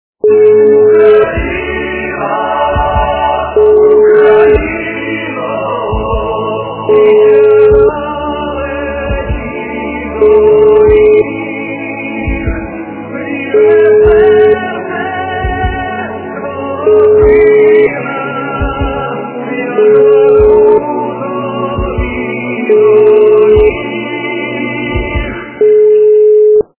- украинская эстрада
качество понижено и присутствуют гудки.